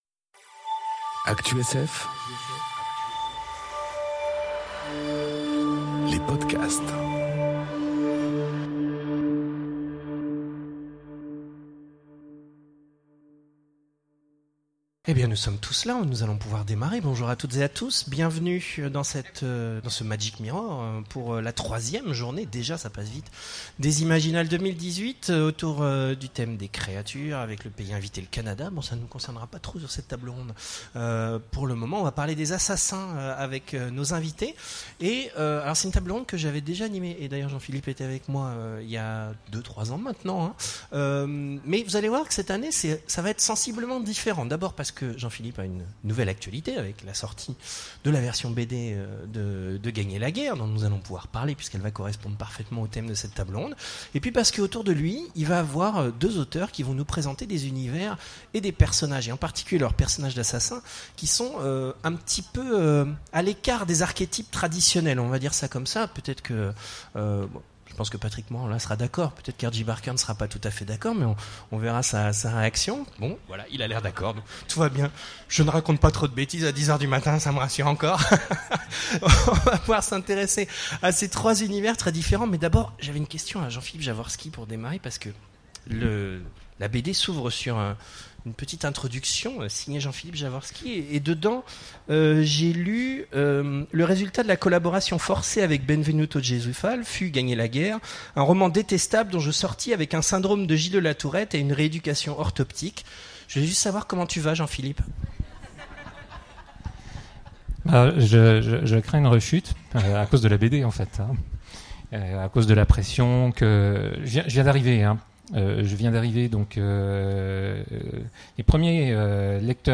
Conférence Tueurs à gages, chasseurs de prime, espions... enregistrée aux Imaginales 2018